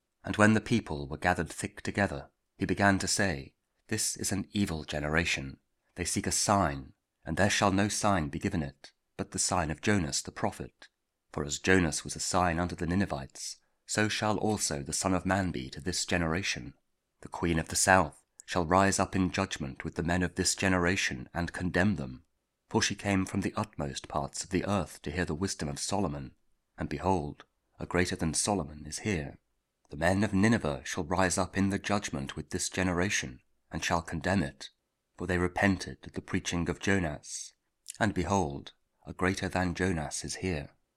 Luke 11: 29-32 – Lent Week 1, Wednesday | Week 28 Ordinary Time, Monday (Audio Bible KJV, Spoken Word)